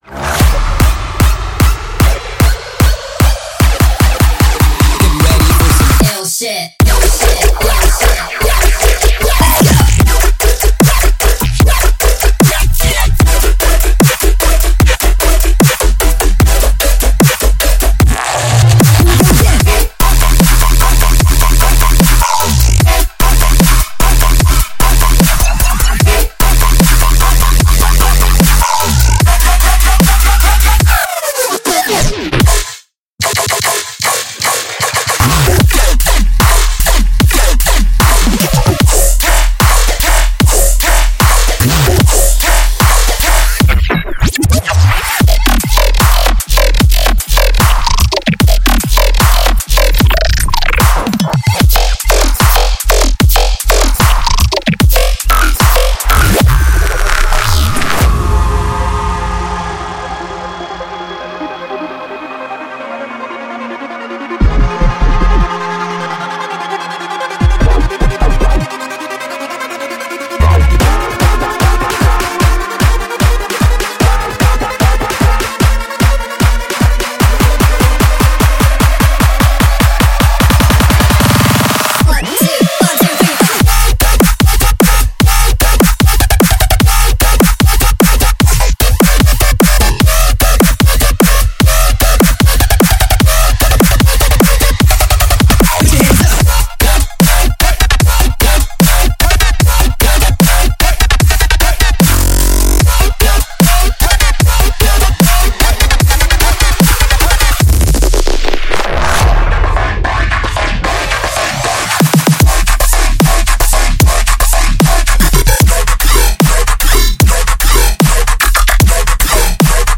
像他的音乐一样，他的背包中充满了郁郁葱葱的声音设计和令人难以置信的标志性低音编程。